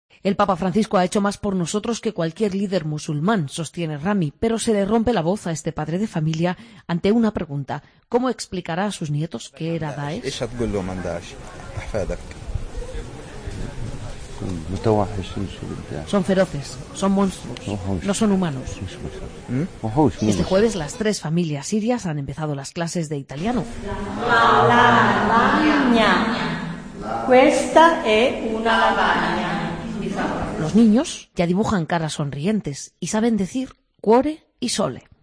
Nuestra corresponsal Paloma Gómez Borrero asiste a una clase de italiano impartida para los refugiados acogidos por el Papa